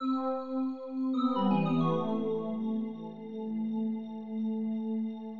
pad 5 (bowed)